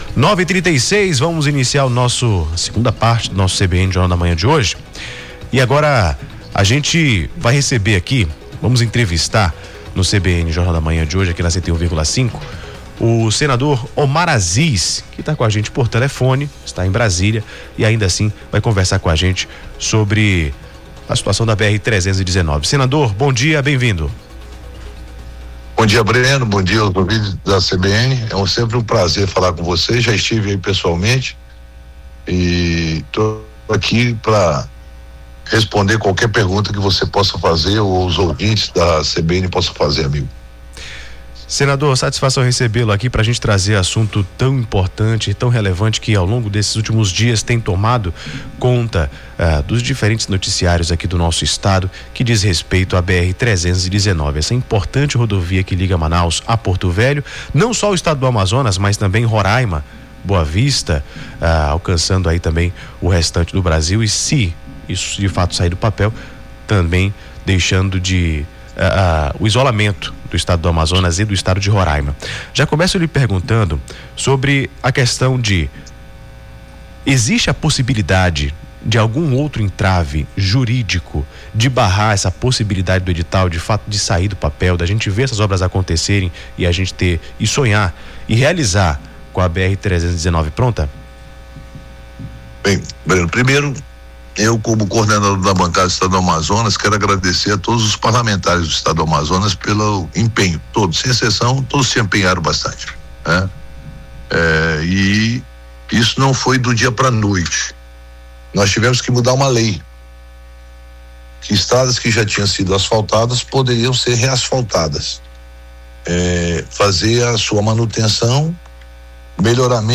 A declaração do senador Omar Aziz foi feita em entrevista à Rádio CBN Amazônia, nesta quinta-feira (02).
ENTREVISTA-COMPLETA---OMAR-AZIZ.mp3